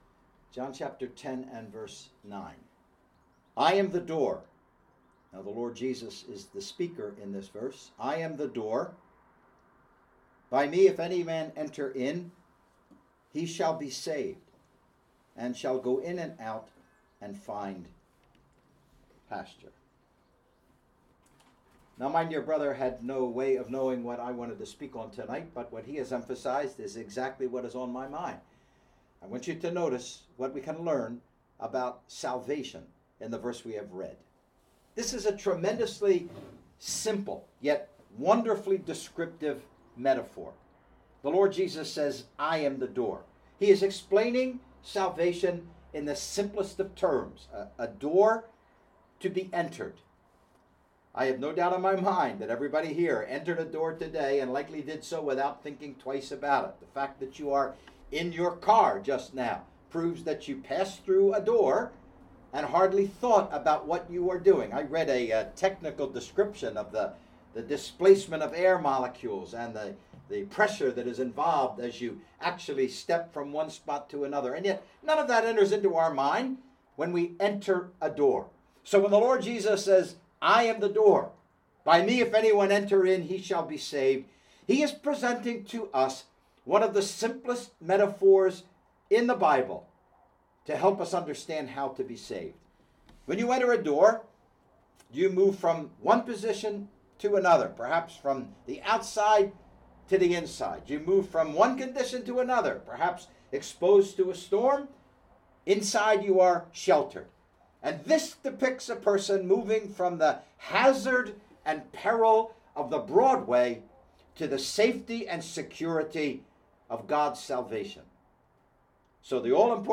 Gospel Meetings ’22: “I am the door” (20 mins)